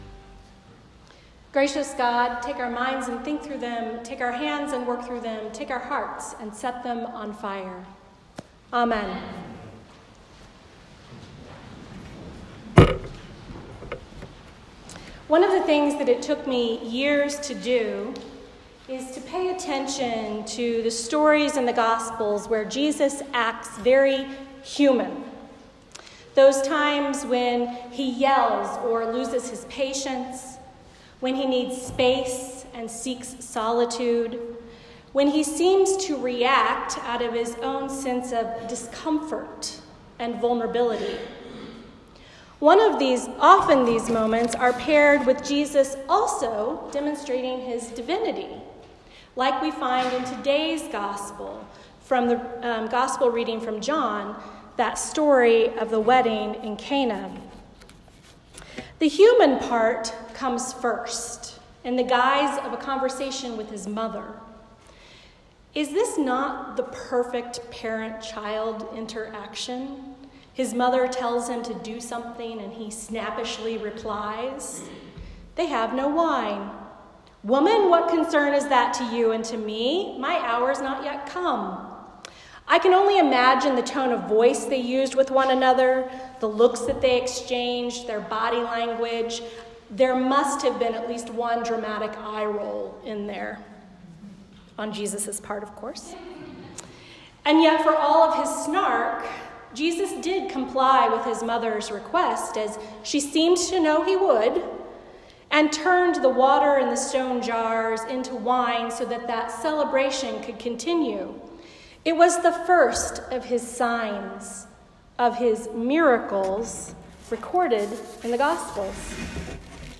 A Sermon for the Second Sunday after the Epiphany